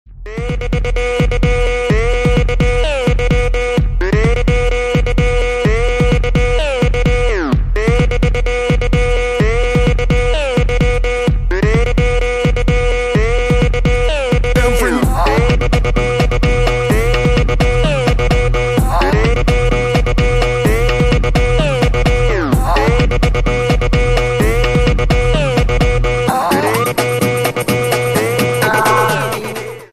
громкие
dance
Electronic
электронная музыка
club
electro house